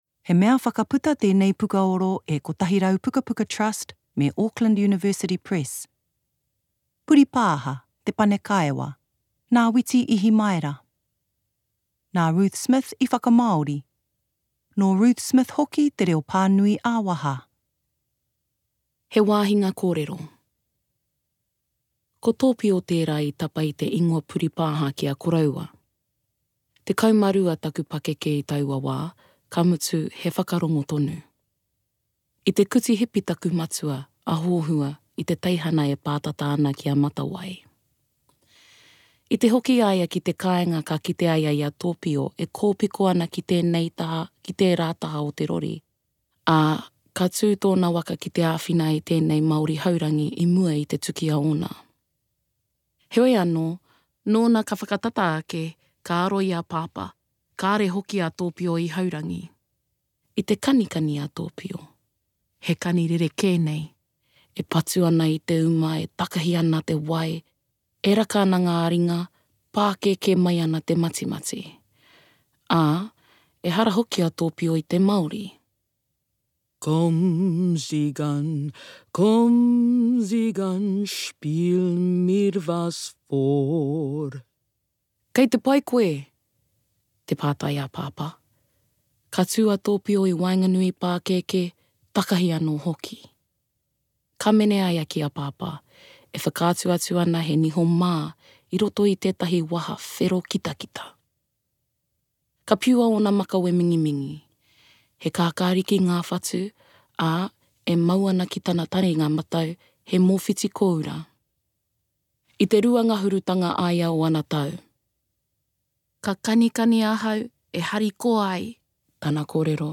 Format: Digital audiobook
Te Kaiwhakamāori / Te Kaipānui / Translator / Narrator